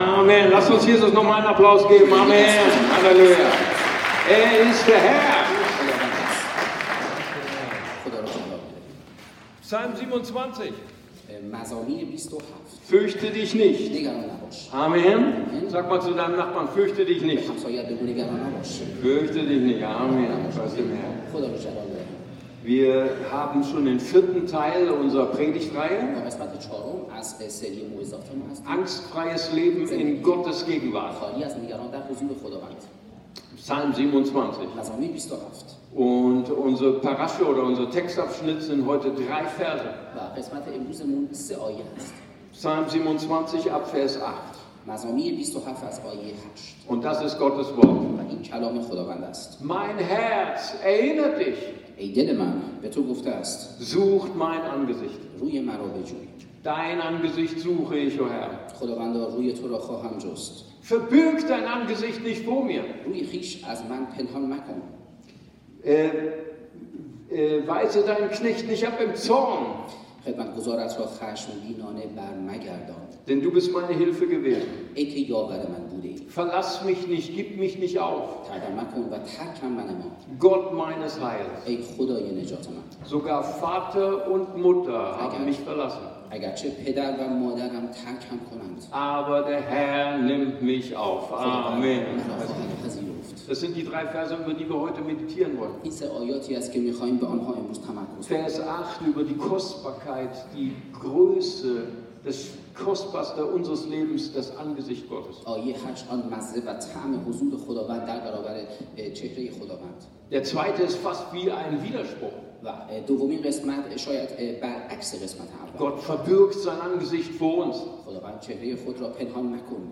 Religion & Spiritualität